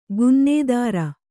♪ gunnēdāra